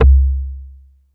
PERCBASSC2.wav